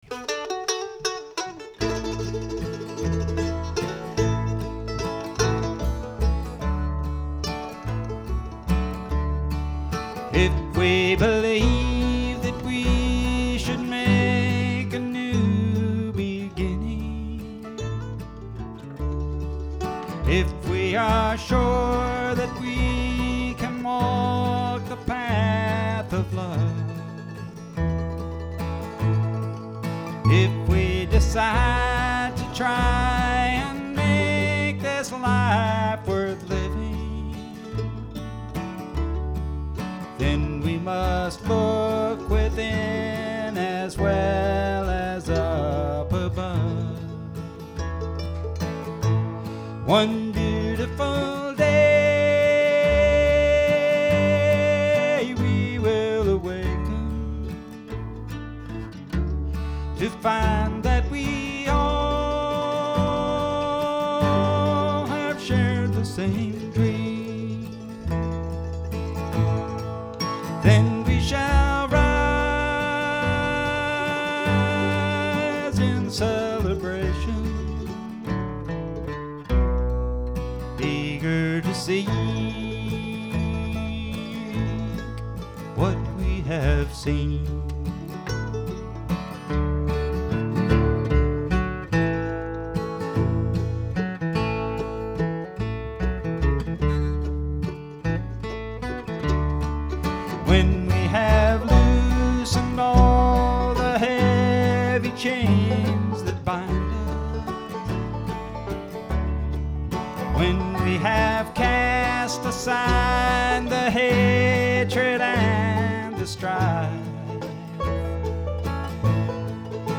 Strong harmonies featuring a capella vocals.